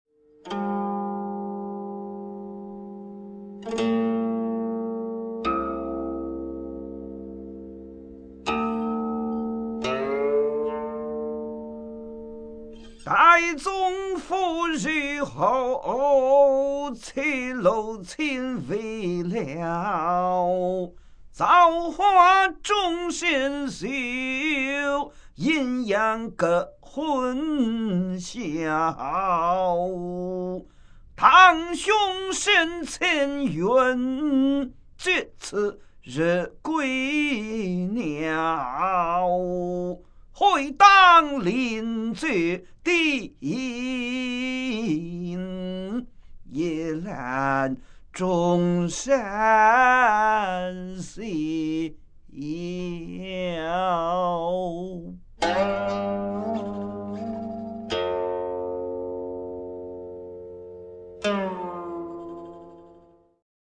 吟哦